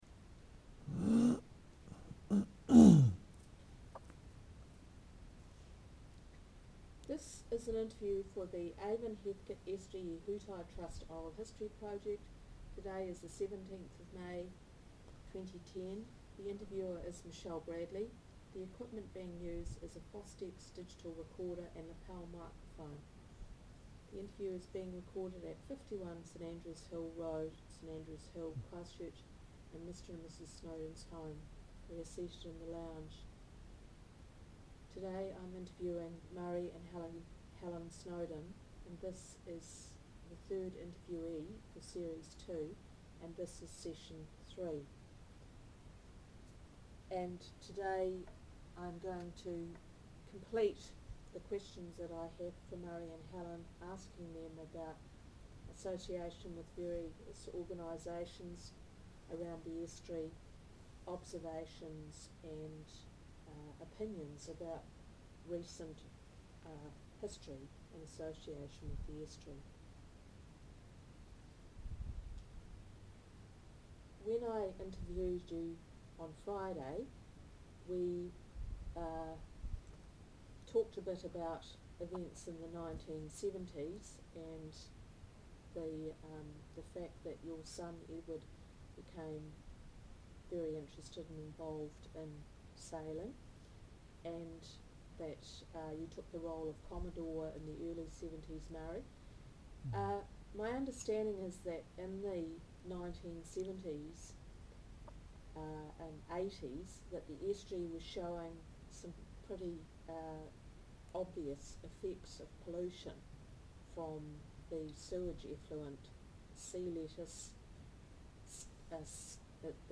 Part of the Avon-Heathcote Estuary Ihutai Trust 2010 Oral History Series.